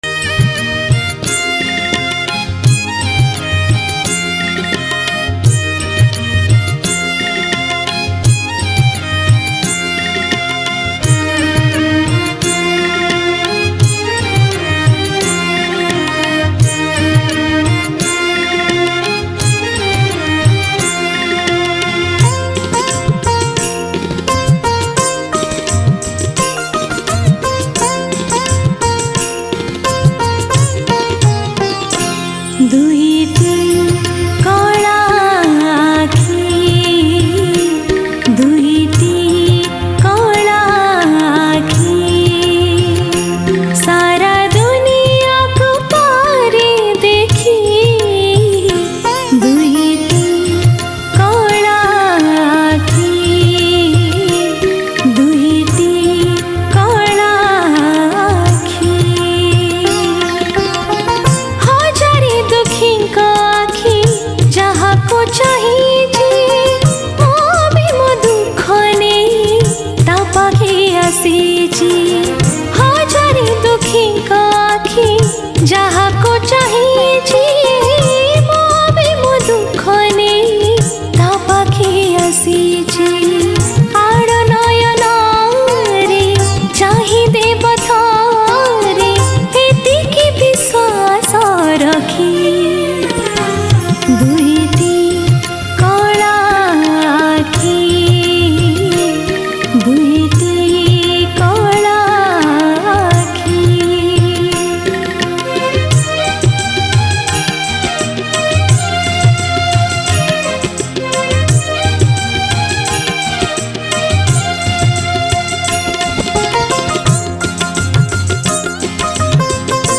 Odia Bhajan Song 2022 Songs Download
Jagannath Bhajan Music